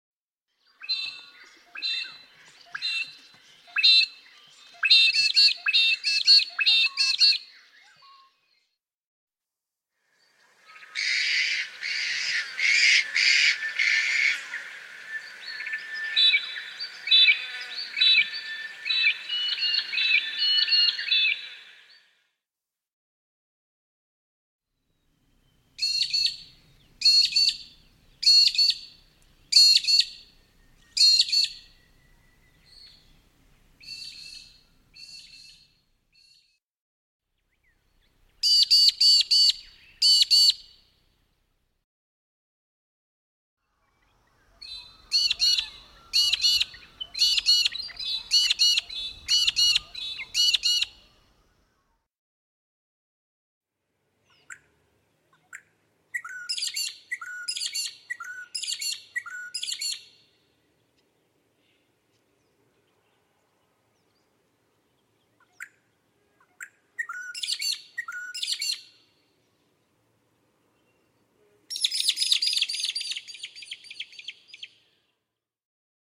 Magpie-lark
Songs & Calls
The Magpie-lark is often referred to as a Peewee or Pee Wee, after the sound of its distinctive calls.
magpie-larkNOM-web.mp3